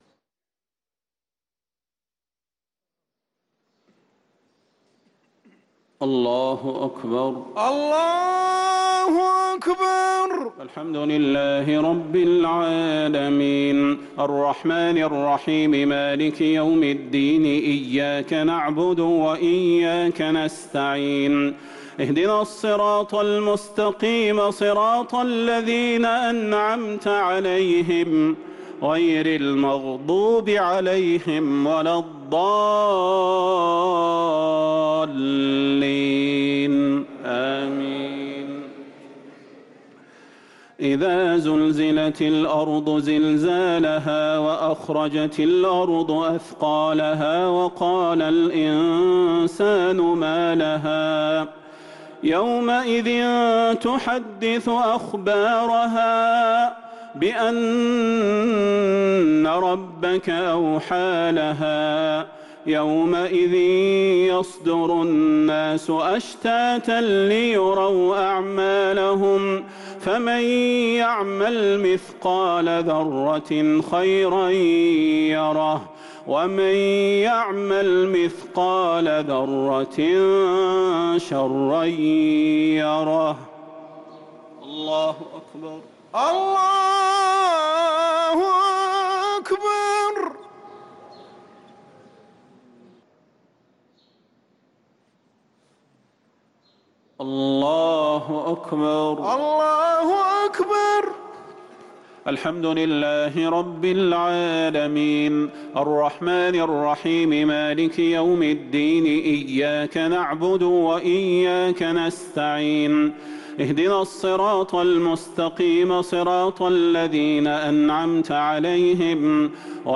الشفع و الوتر ليلة 28 رمضان 1444هـ | Witr 28 st night Ramadan 1444H > تراويح الحرم النبوي عام 1444 🕌 > التراويح - تلاوات الحرمين